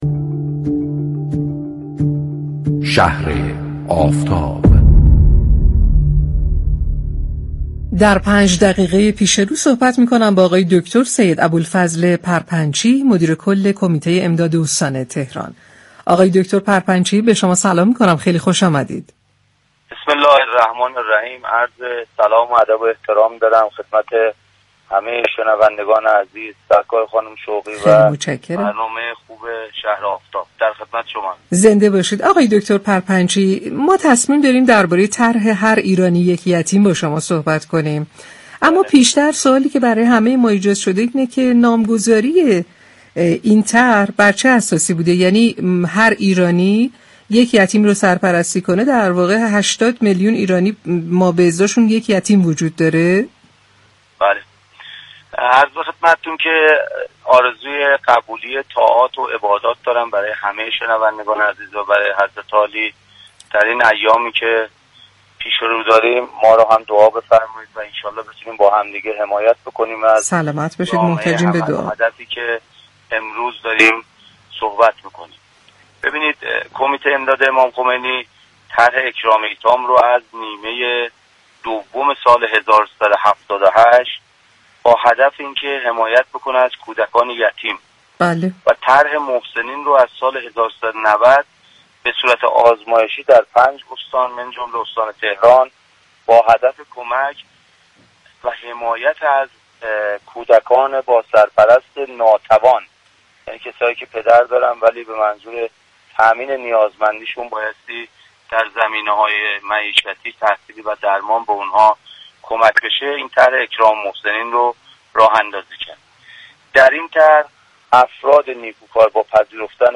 به گزارش پایگاه اطلاع رسانی رادیو تهران، سید ابوالفضل پرپنچی مدیر كل كمیته امداد استان تهران در گفت و گو با «شهر آفتاب» درخصوص اجرای طرح «هر ایرانی، یك یتیم» اظهار داشت: كمیته امداد امام خمینی(ره)، طرح اكرام ایتام را از نیمه دوم سال 1378 با هدف حمایت از كودكان یتیم و طرح محسنین را از سال 1390 به صورت آزمایشی در 5 استان از جمله استان تهران با هدف كمك و حمایت از كودكان با سرپرست ناتوان یعنی كسانی كه پدر دارند، ولی به منظور تامین نیازمندی شان بایستی در زمینه‌های معیشتی، تحصیلی و درمان به آن‌ها كمك شود راه اندازی كردیم.